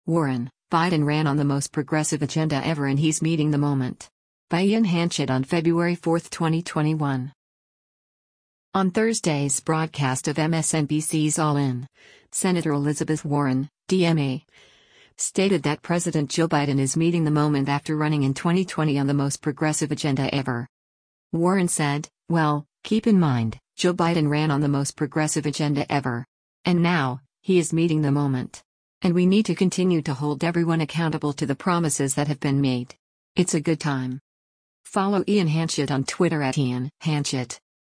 On Thursday’s broadcast of MSNBC’s “All In,” Sen. Elizabeth Warren (D-MA) stated that President Joe Biden “is meeting the moment” after running in 2020 “on the most progressive agenda ever.”